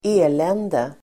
Uttal: [²'e:len:de]